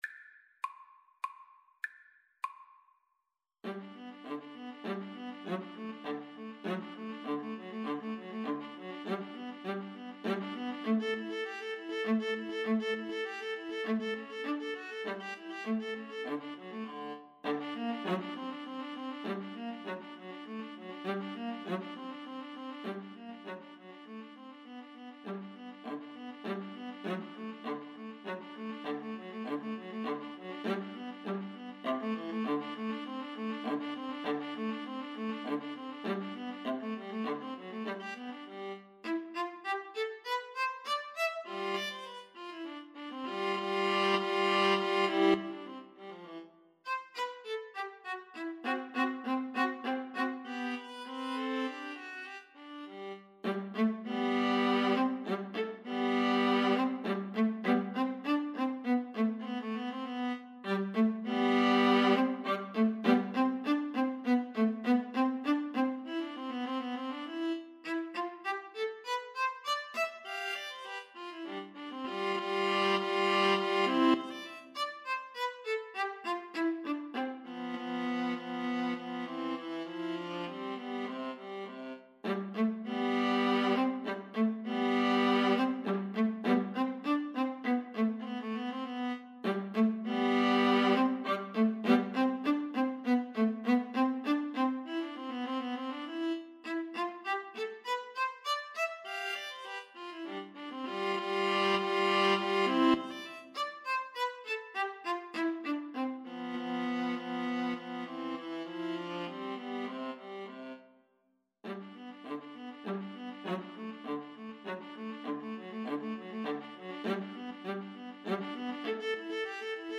Moderato
Classical (View more Classical Violin-Viola Duet Music)